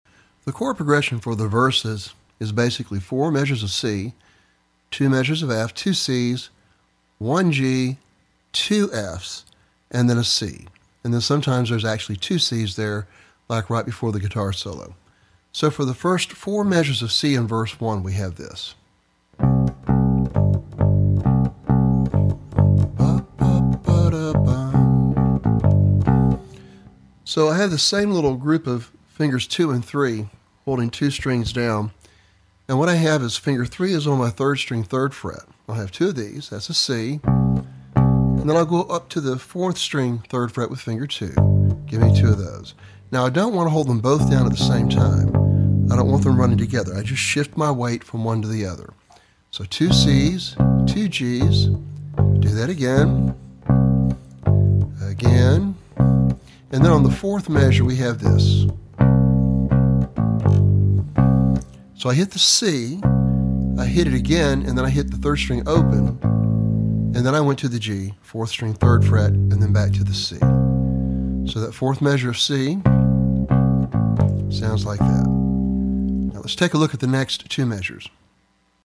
For Bass Guitar.